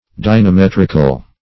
Dynametrical \Dy`na*met"ric*al\